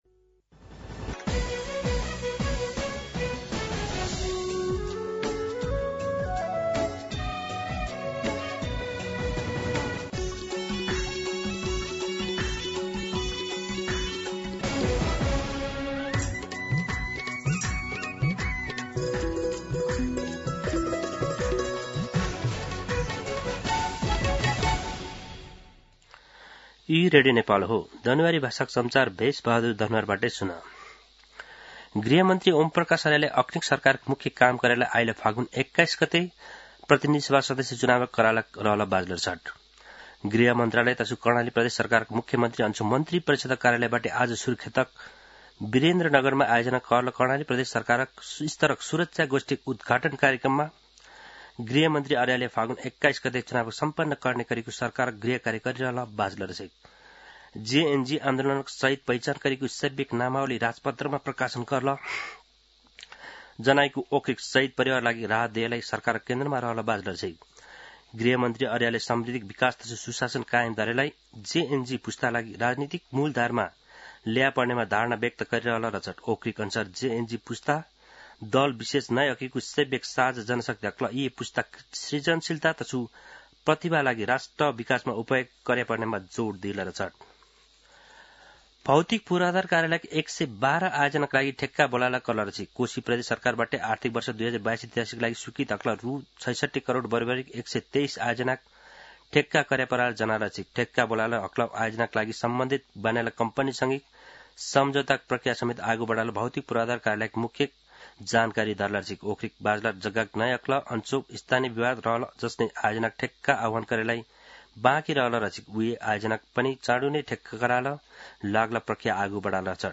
दनुवार भाषामा समाचार : ९ पुष , २०८२
Danuwar-News.mp3